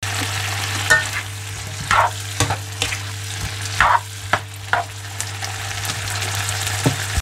Tiếng Trộn, Trở, Xào, Nấu… đồ ăn trên Chảo trong bếp
Thể loại: Tiếng ăn uống
Description: Tiếng Trộn, Trở, Xào, Nấu… đồ ăn trên Chảo trong bếp – một bản sound effect mp3 cực sống động, tái hiện rõ nét tiếng chảo kêu xèo xèo, tiếng đảo thức ăn, âm thanh dầu sôi… Tất cả hòa quyện thành một bản âm thanh nền lý tưởng cho các cảnh quay bếp núc, vlog ẩm thực hay phim ngắn.
tieng-tron-tro-xao-nau-do-an-tren-chao-trong-bep-www_tiengdong_com.mp3